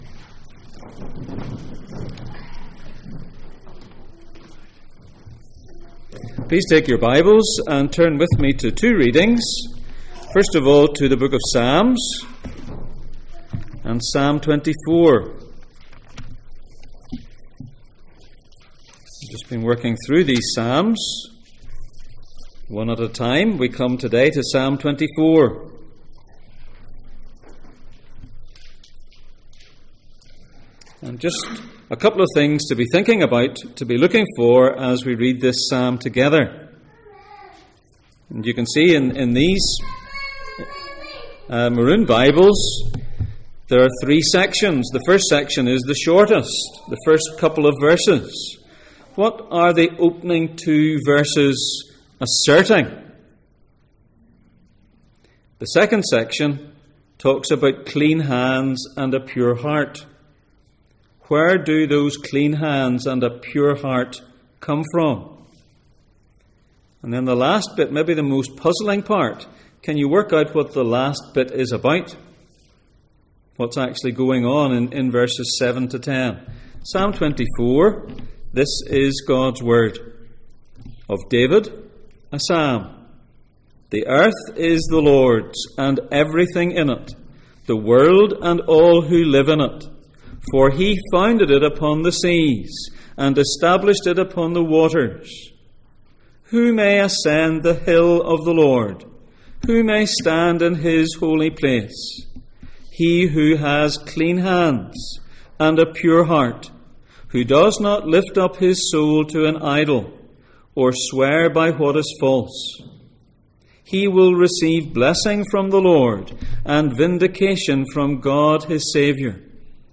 Psalms Passage: Psalm 24:1-10, John 12:12-16 Service Type: Sunday Morning %todo_render% « Constant